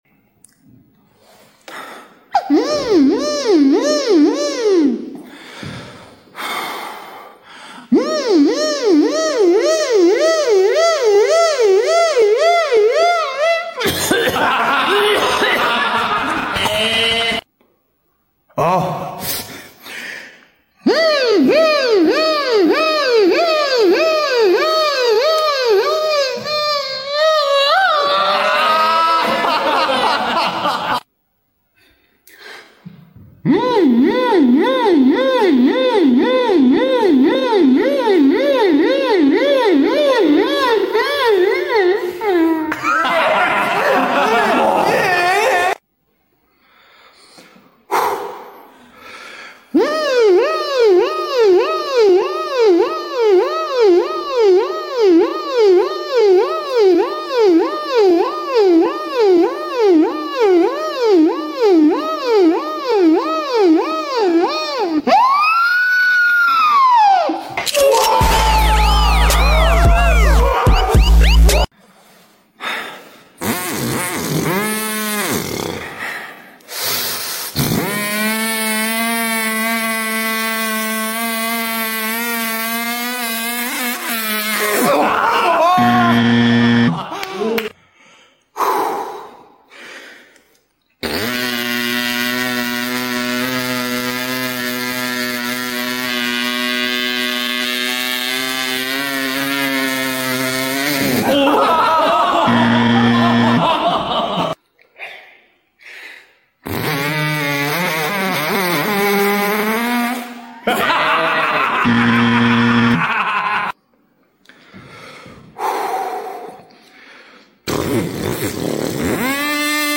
When the Police Siren 🚨 sound effects free download
When the Police Siren 🚨 Beatbox meets the Chainsaw Challenge!